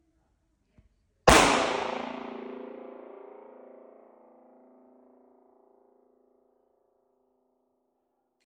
Elektronické přílohy: 1a - třepotavka tělocvična č. 1 pistole.mp3